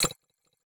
Futuristic Sounds (17).wav